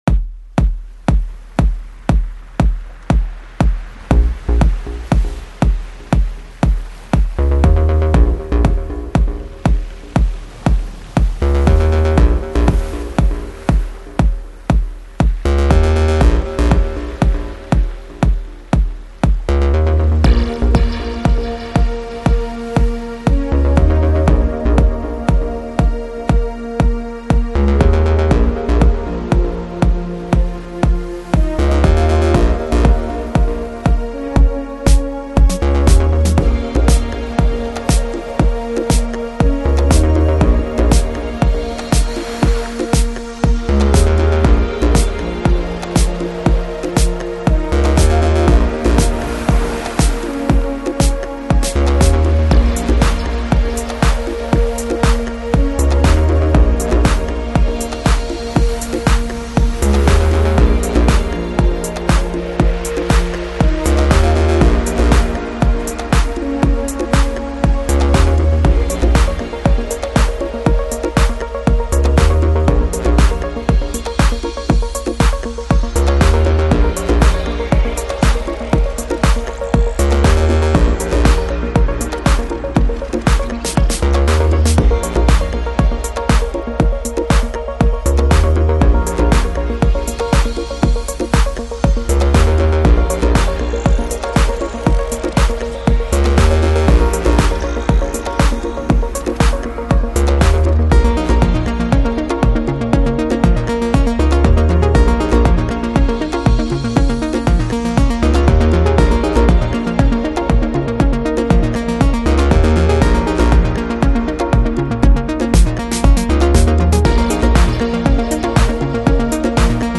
Electronic, Lounge, Chill Out, Downtempo